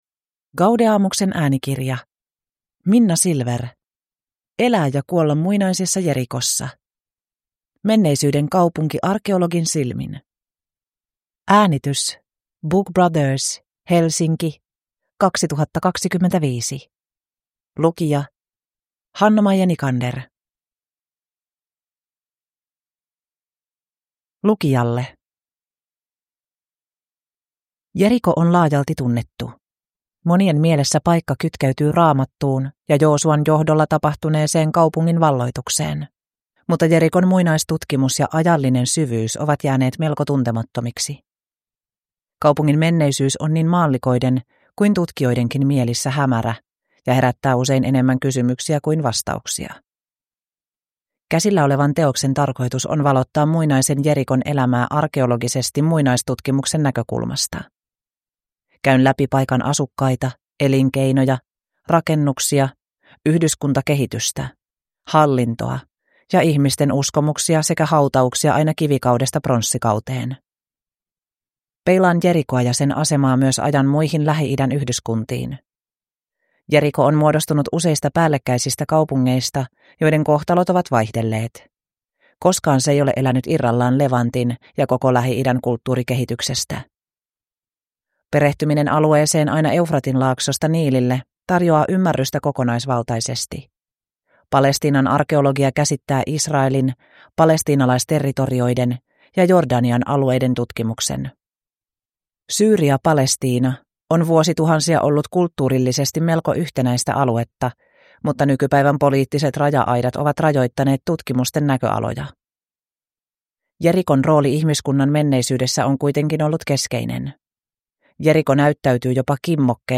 Elää ja kuolla muinaisessa Jerikossa (ljudbok) av Minna Silver